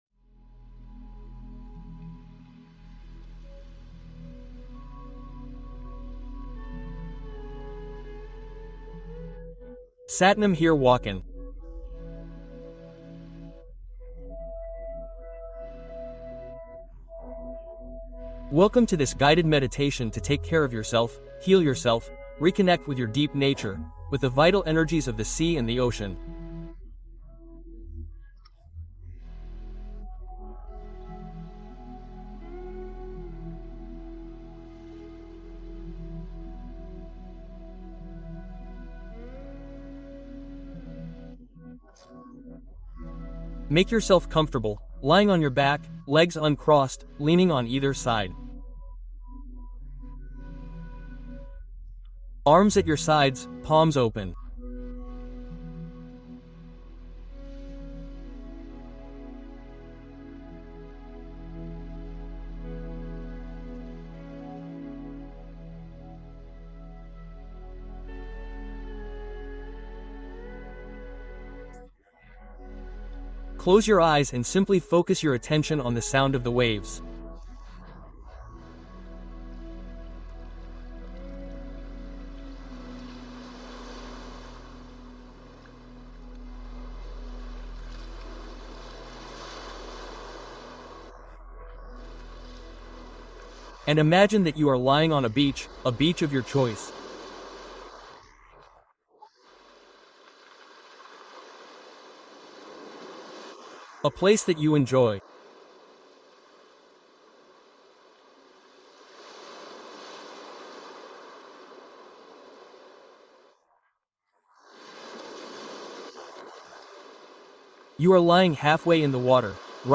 Sérénité absolue : méditation de relaxation profonde pour lâcher prise complètement